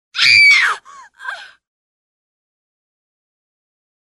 GIRL SCREAM